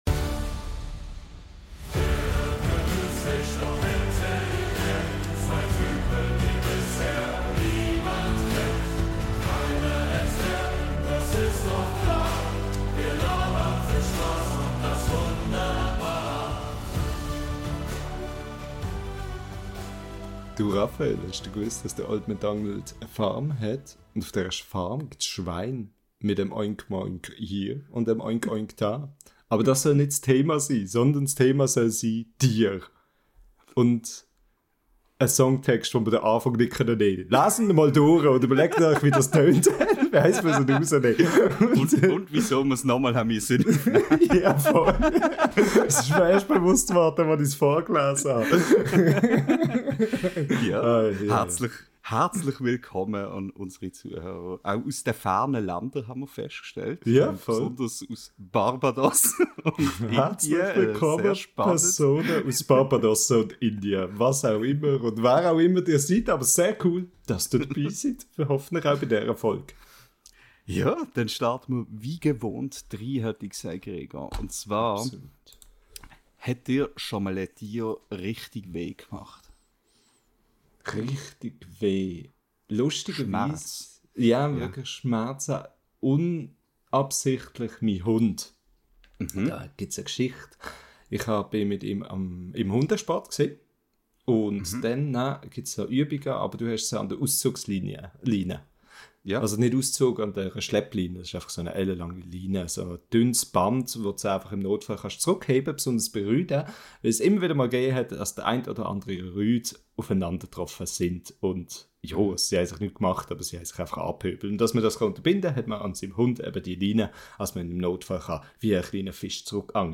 Wir sinnieren in gewohnt chaotisch-schweizerischer Manier über alles, was kreucht, fleucht und sich eventuell gut im Taschenformat machen würde.